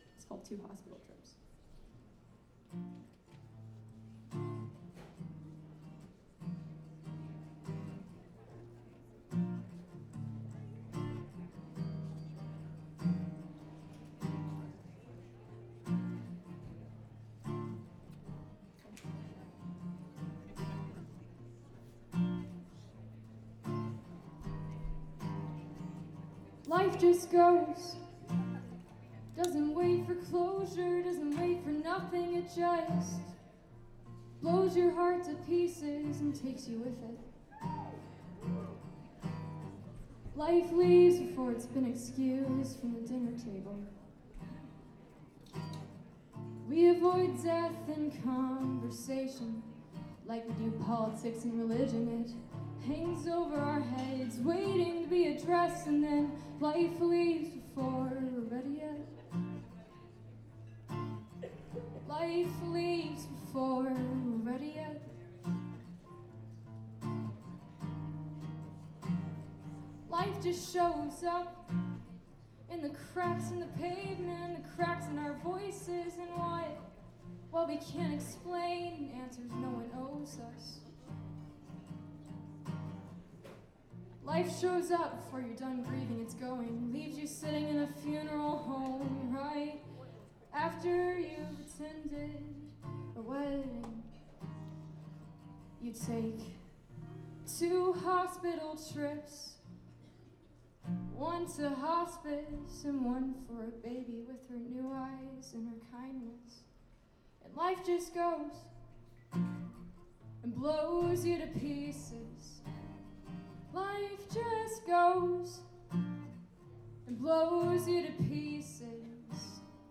lifeblood: bootlegs: 2016-01-03: terminal west - atlanta, georgia (benefit for save the libraries)